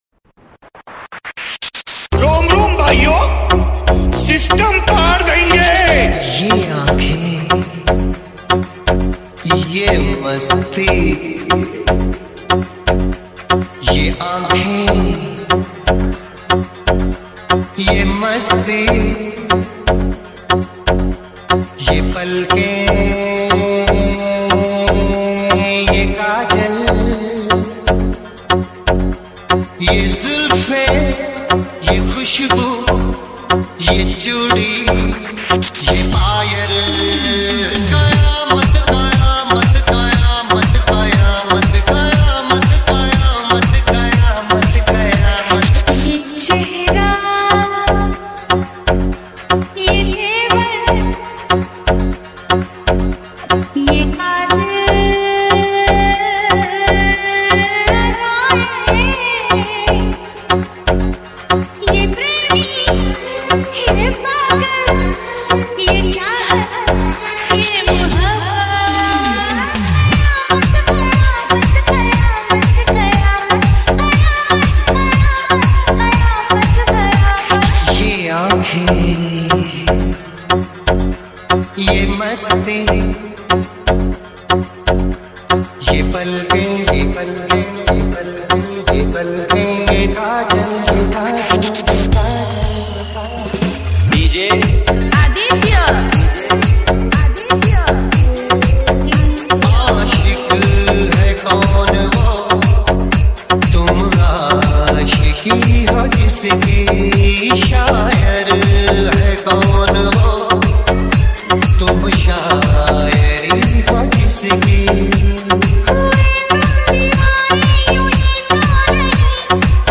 Car complete system installed big